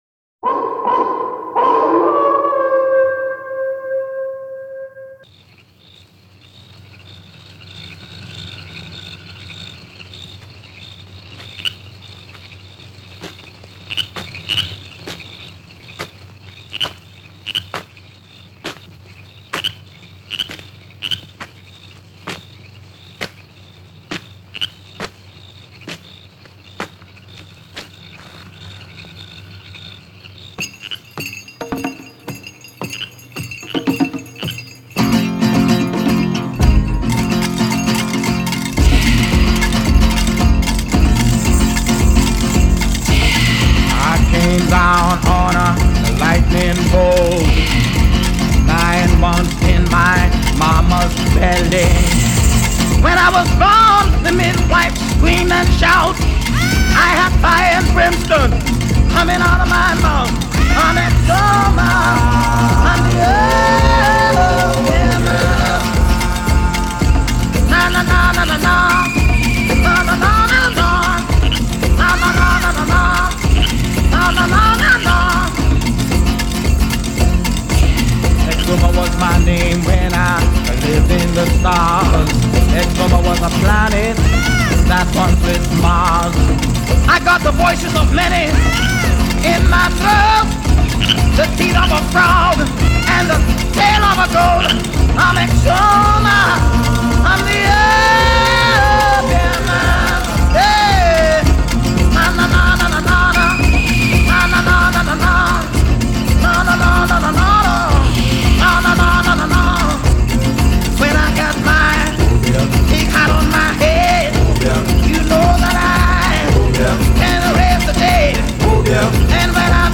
aux sonorités psychédéliques